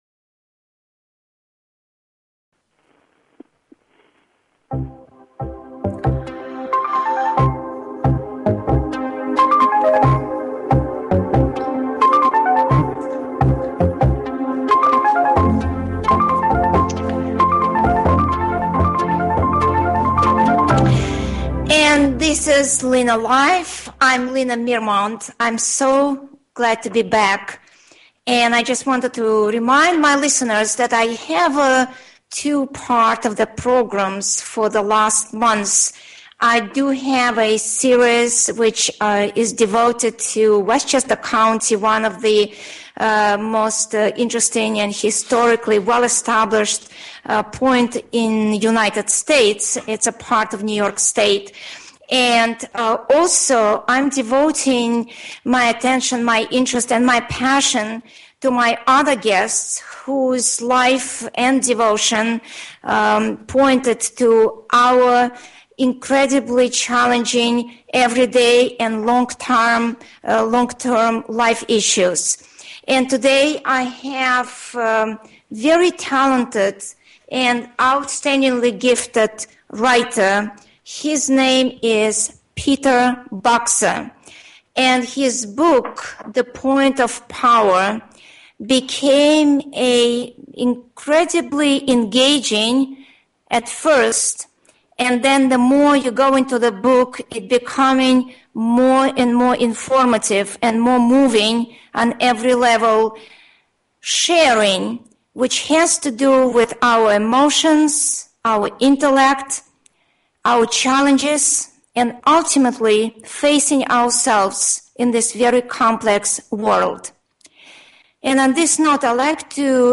Courtesy of BBS Radio
Talk Show